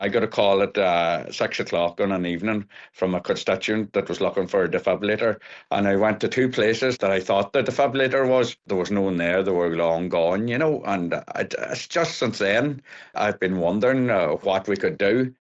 Senator Boyle says he has personal experience and knows every second counts: